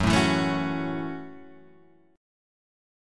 F#m7 chord {2 0 2 2 2 0} chord